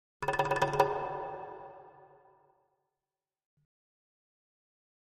Jungle Drums Fear Fast Thin Hits Version 3